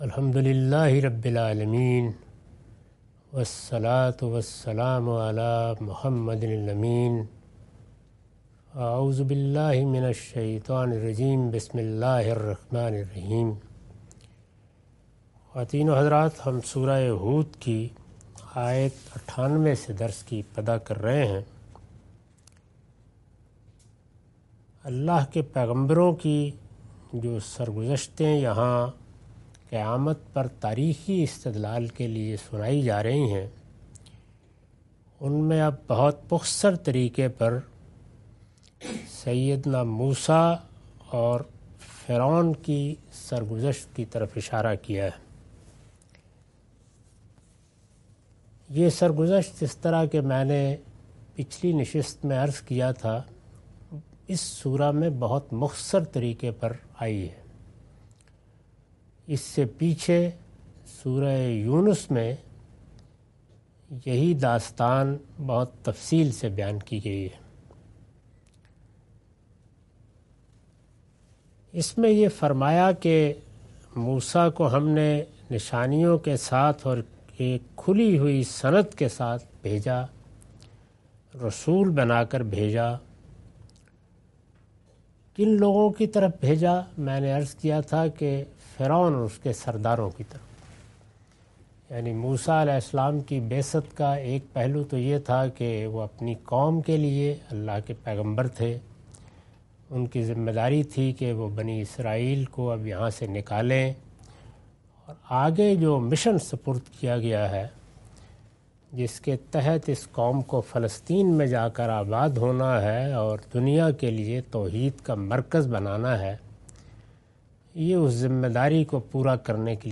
Surah Hud- A lecture of Tafseer-ul-Quran – Al-Bayan by Javed Ahmad Ghamidi. Commentary and explanation of verses 98-105.